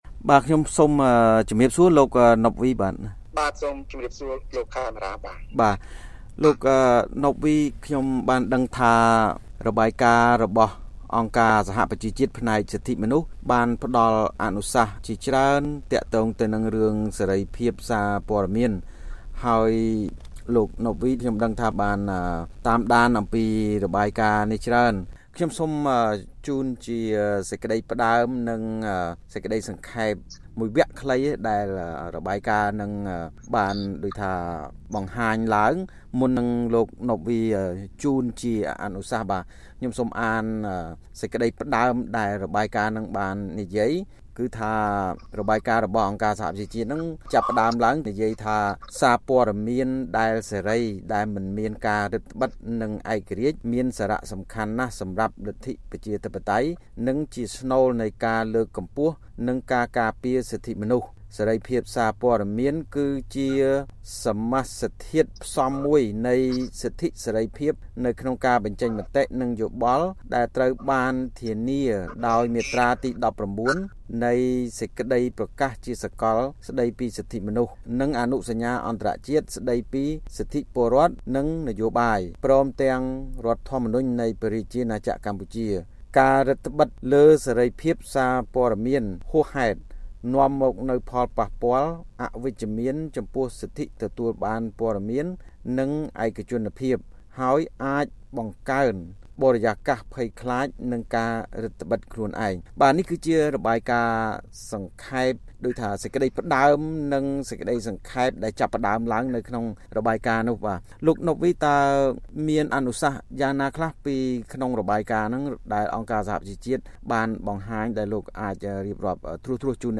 បទសម្ភាសន៍៖ អ្នកជំនាញចង់ឃើញកម្ពុជាលើកកម្ពស់ស្ថានភាពសេរីភាពសារព័ត៌មានតាមរបាយការណ៍អ.ស.ប